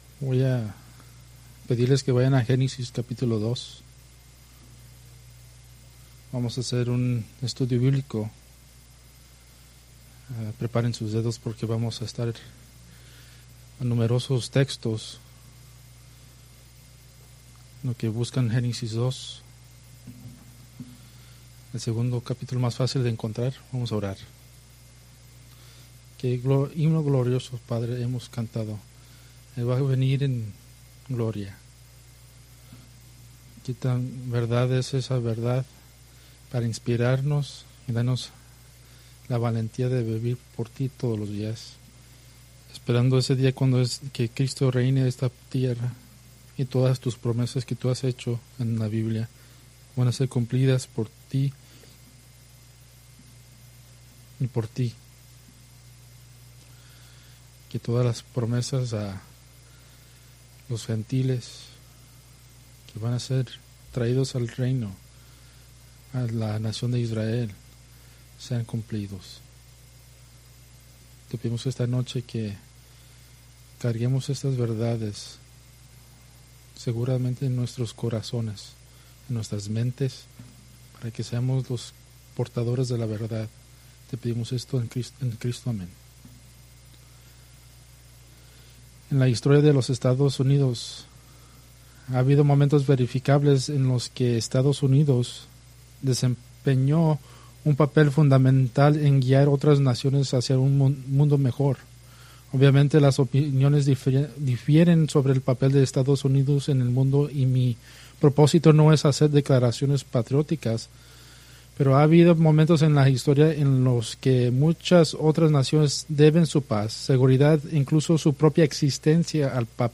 Preached April 12, 2026 from Escrituras seleccionadas